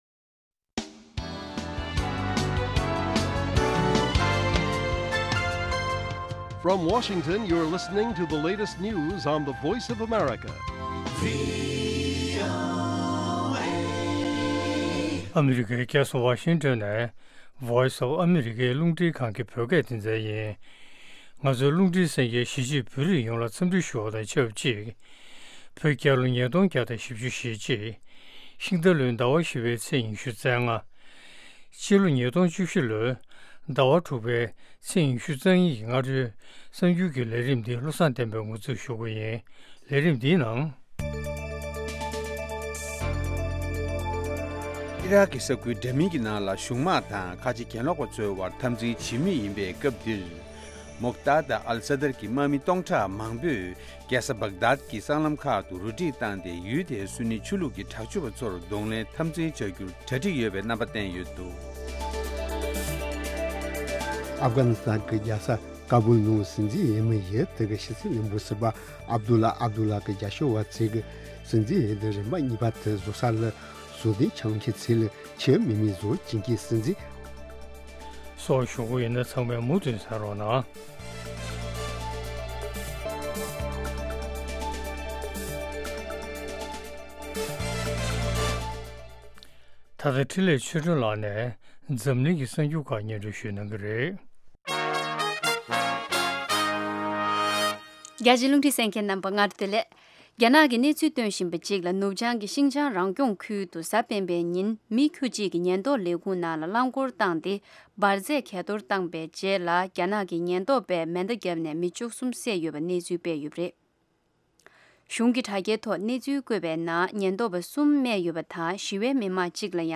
སྔ་དྲོའི་གསར་འགྱུར། ཉིན་ལྟར་ཐོན་བཞིན་པའི་བོད་དང་ཨ་རིའི་གསར་འགྱུར་ཁག་དང་། འཛམ་གླིང་གསར་འགྱུར་ཁག་རྒྱང་སྲིང་ཞུས་པ་ཕུད། དེ་མིན་དམིགས་བསལ་ལེ་ཚན་ཁག་ཅིག་རྒྱང་སྲིང་ཞུ་བཞིན་ཡོད། རྒྱང་སྲིང་དུས་ཚོད། Daily བོད་ཀྱི་དུས་ཚོད། 08:00 འཛམ་གླིང་གཅིག་གྱུར་གྱི་དུས་ཚོད། 0000 ལེ་ཚན་རིང་ཐུང་། 60 གསན་ན། MP༣ Podcast